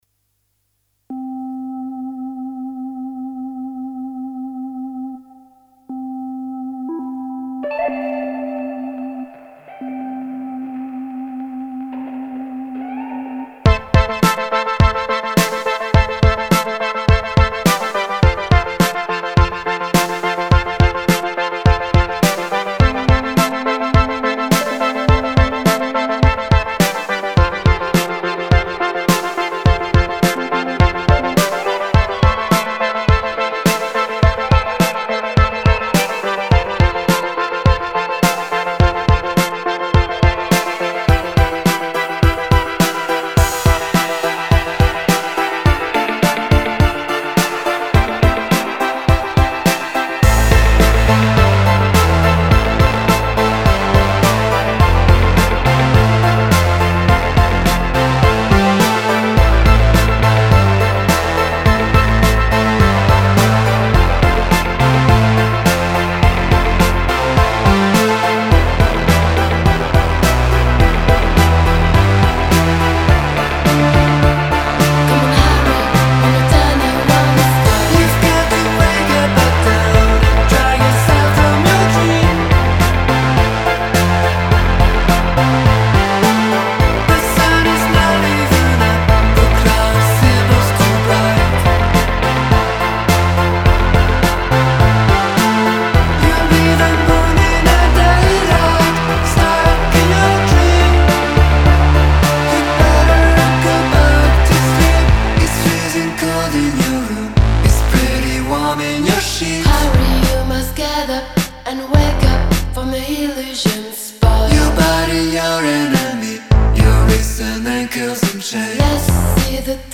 Chaloupé, moderne et exigeant
synthétiseurs, boîtes-à-rythmes